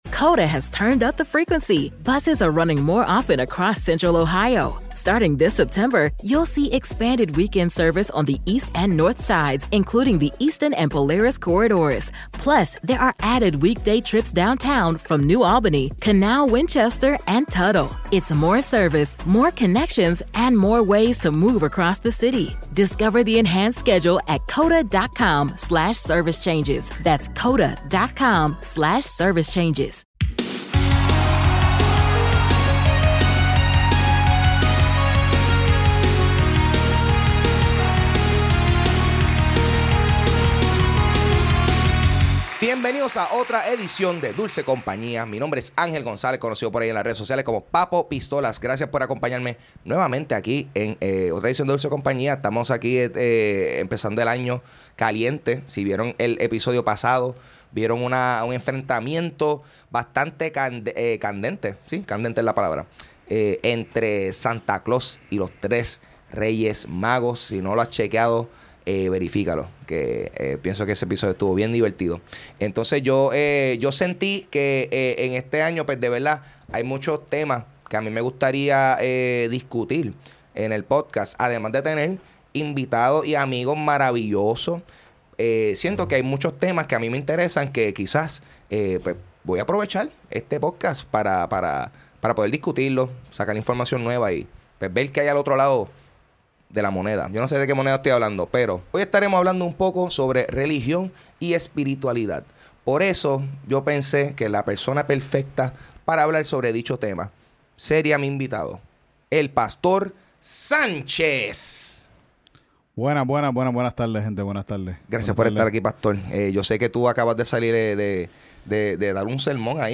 Una conversación